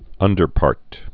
(ŭndər-pärt)